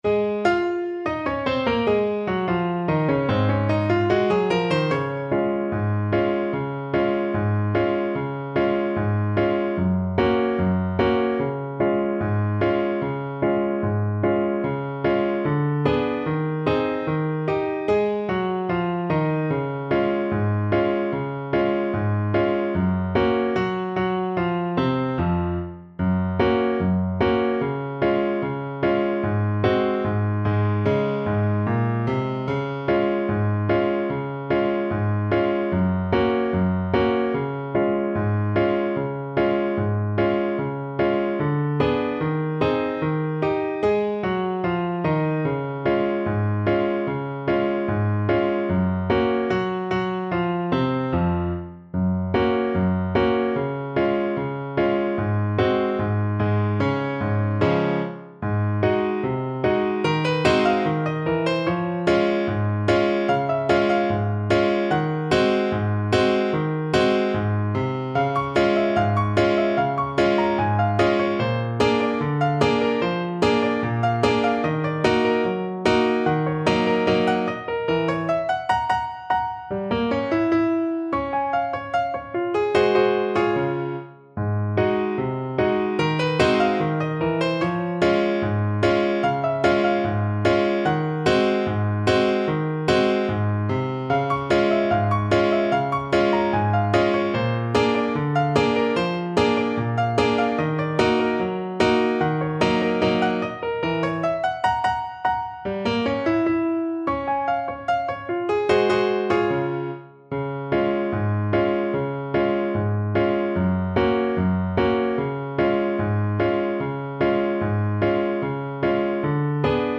Play (or use space bar on your keyboard) Pause Music Playalong - Piano Accompaniment Playalong Band Accompaniment not yet available transpose reset tempo print settings full screen
Db major (Sounding Pitch) Bb major (Alto Saxophone in Eb) (View more Db major Music for Saxophone )
Not Fast = 74